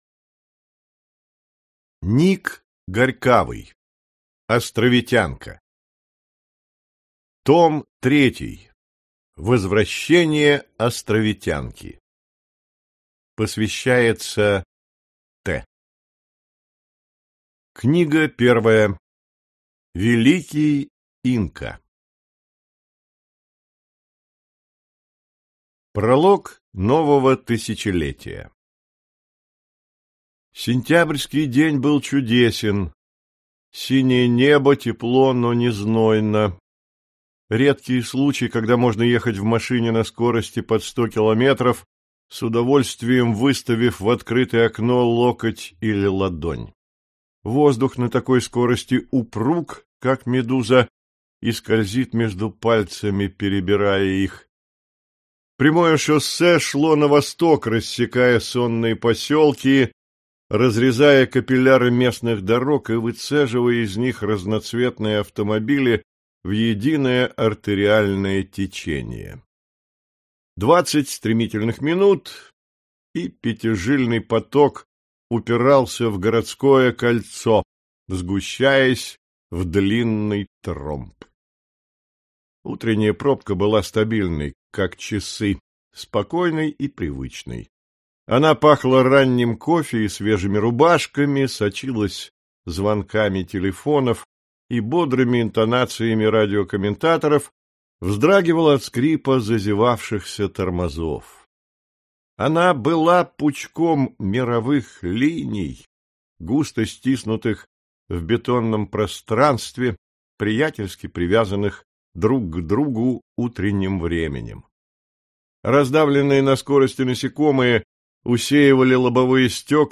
Аудиокнига Возвращение астровитянки. Книга 1. Великий Инка | Библиотека аудиокниг